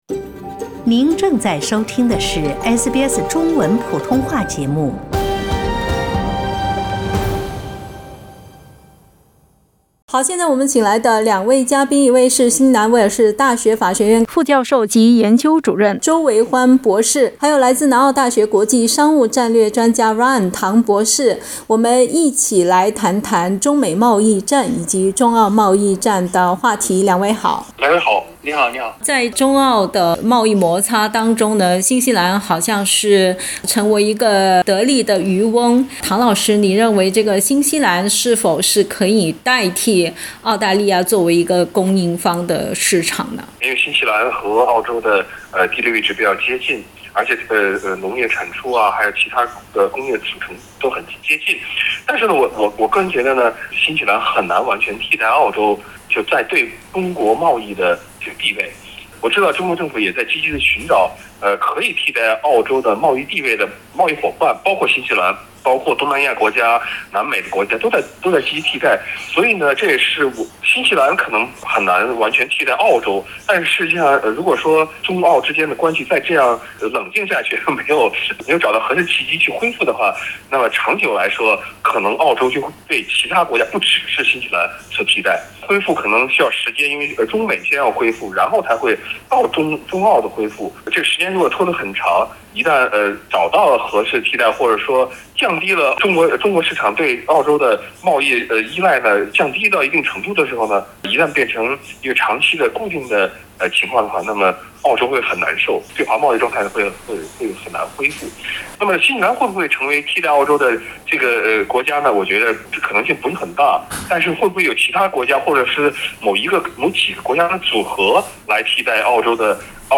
(点击上图收听采访) 以上分析仅为嘉宾观点，不代表本台立场 澳大利亚人必须与他人保持至少1.5米的社交距离，请查看您所在州或领地的最新社交限制措施。